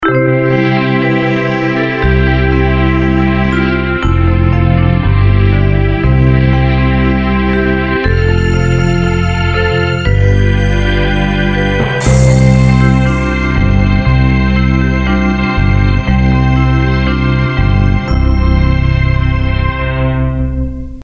Intro Music)